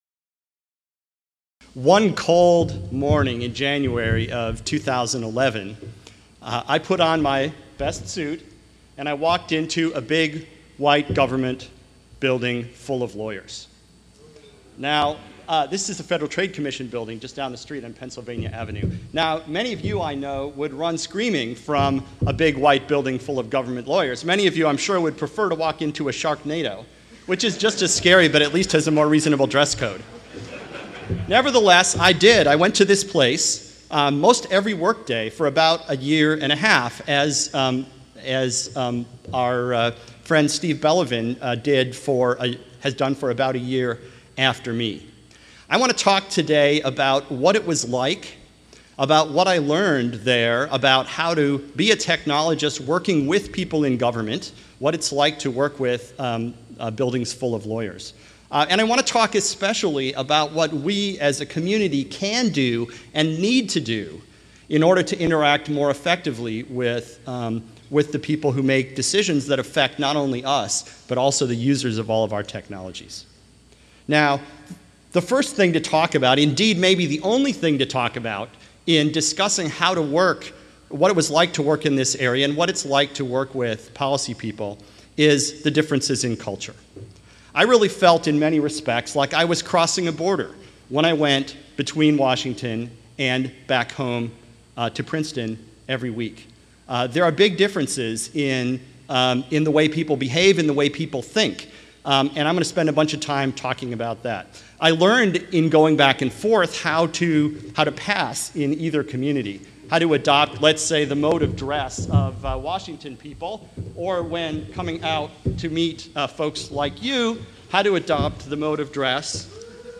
This talk will describe the speaker's experience in government, where he served as the first Chief Technologist at the Federal Trade Commission.